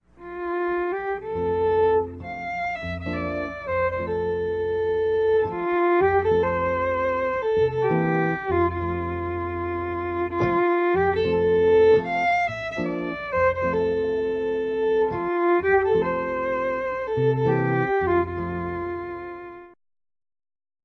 guitarist
a collection of four traditional Irish tunes